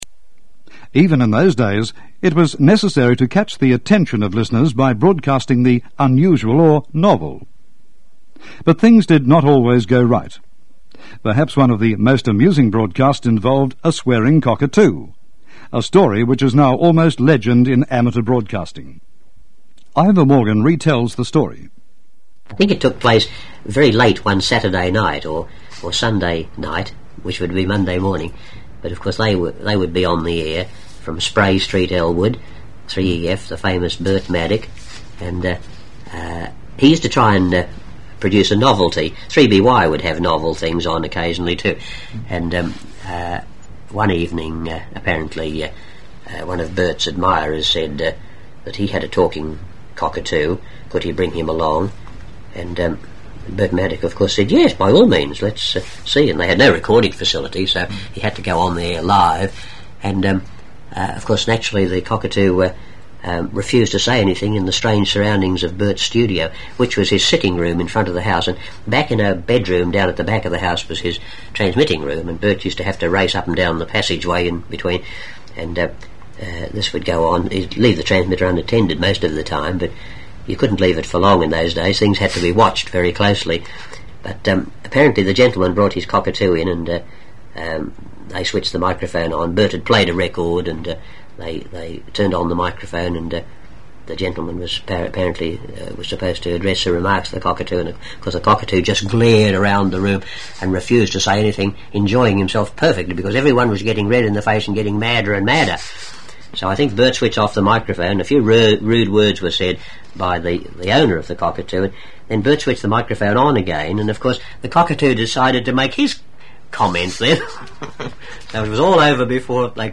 This recorder scribed the signal grooves directly into the soft aluminium disc.